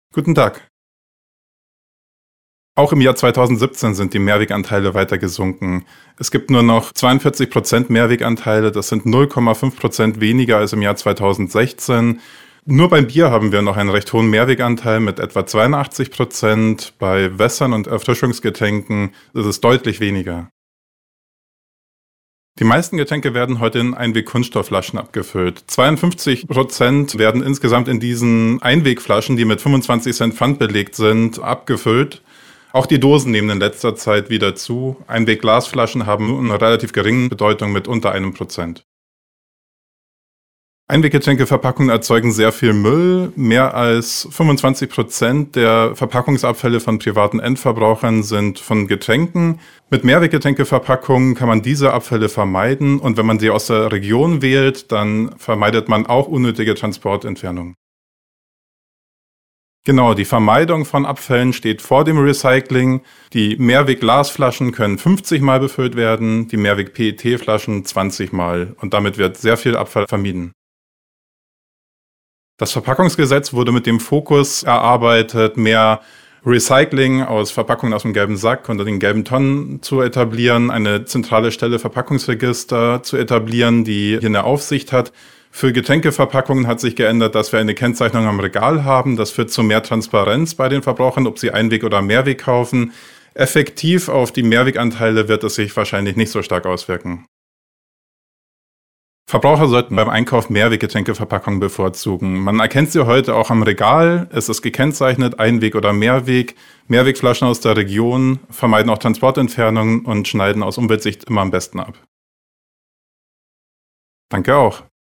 Interview: 1:55 Minuten